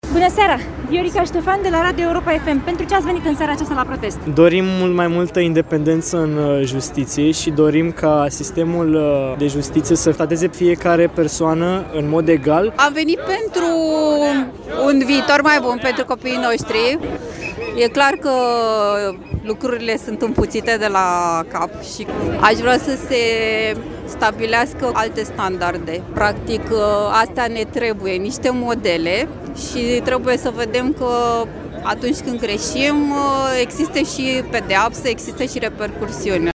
Am venit pentru un viitor mai bun pentru copiii noștri”, spune o femeie